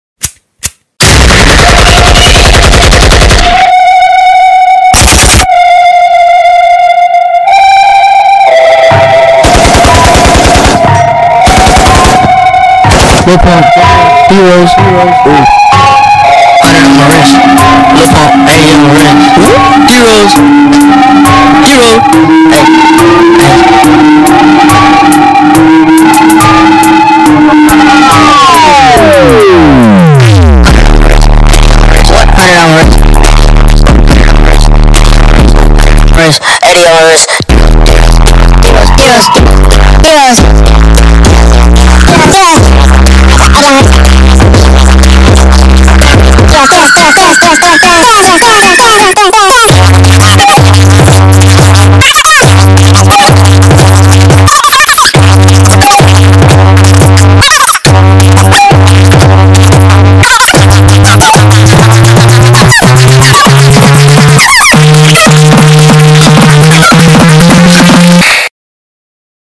На этой странице собраны звуки, которые могут раздражать кошек и собак: высокочастотные сигналы, резкие шумы, ультразвук.